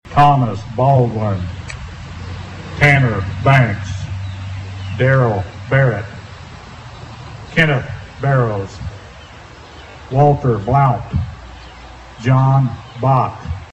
Under a partly cloudy sky, Emporians gathered at the All Veterans Memorial to pay their respects to military service personnel who never made it home from combat as well as those who passed away over the last year.
1998-names.mp3